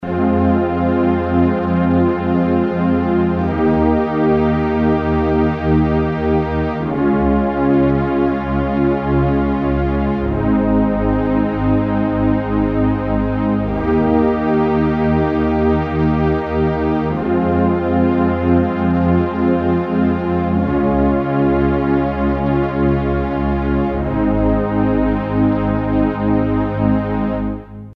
softpad
Synthesis: FM
softpad.mp3